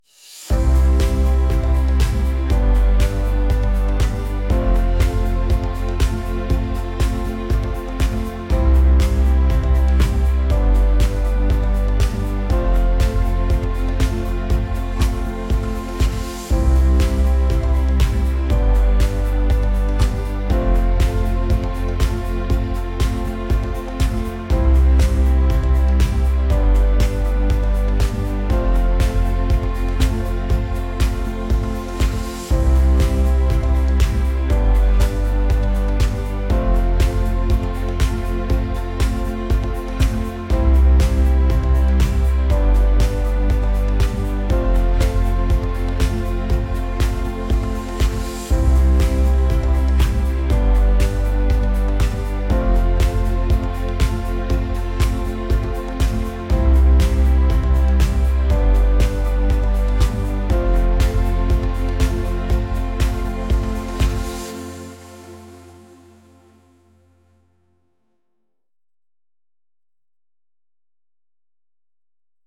pop | acoustic | indie